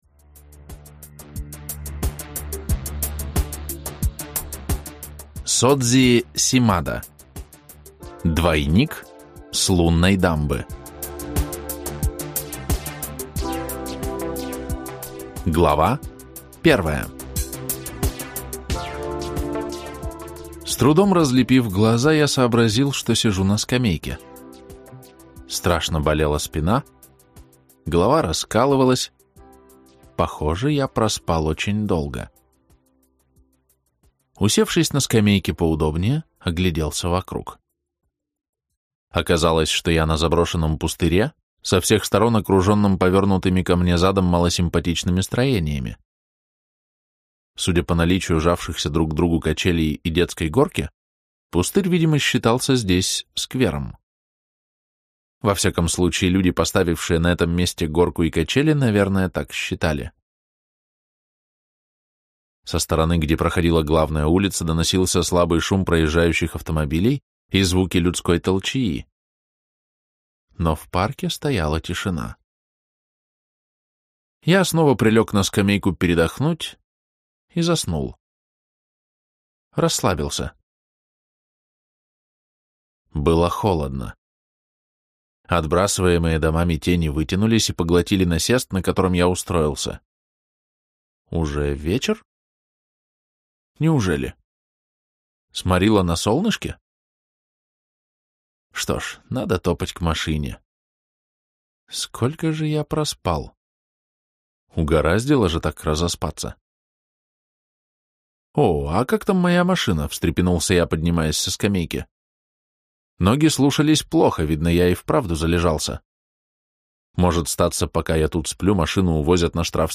Аудиокнига Двойник с лунной дамбы | Библиотека аудиокниг
Прослушать и бесплатно скачать фрагмент аудиокниги